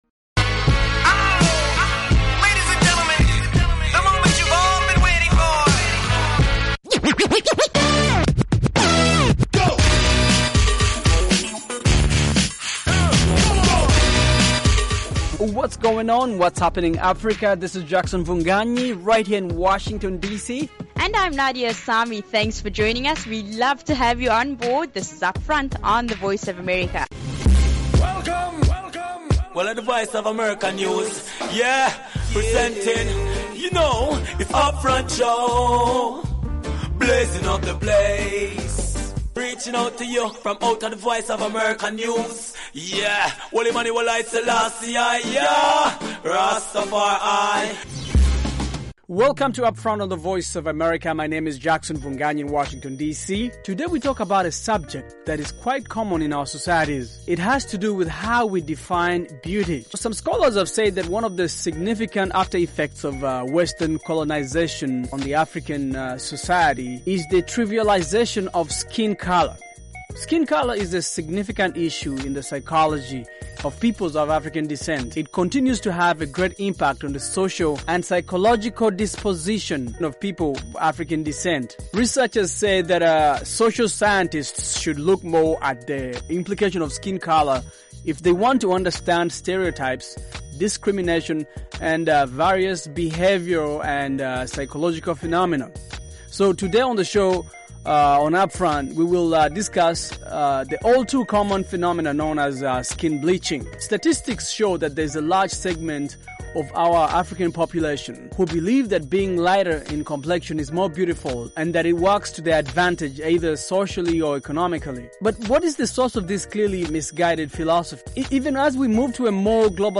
On this fresh, fast-paced show